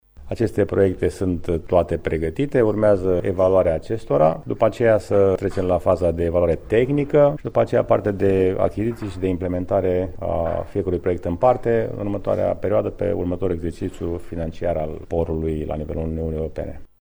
Care este stadiul acestor proiecte, ne-a spus edilul municipiului Brașov, George Scripcaru: